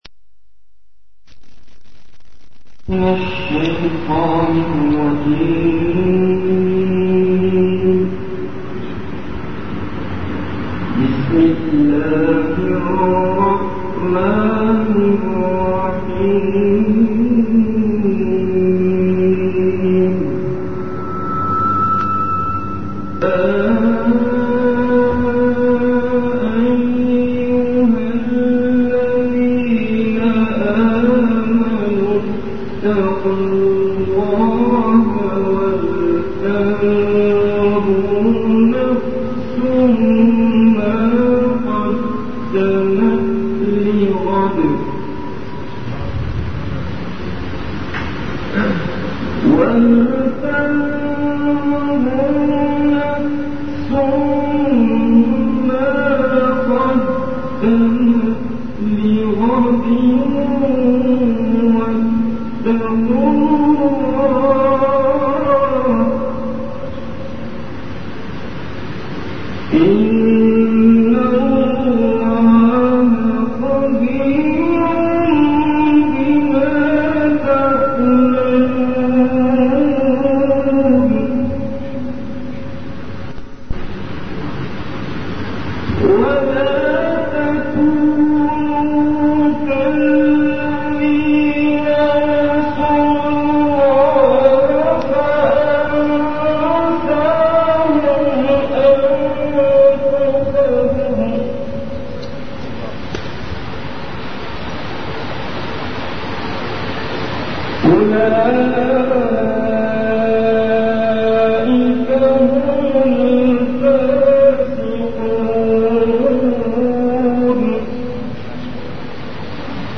Majlis-e-Zikr
Event / Time After Isha Prayer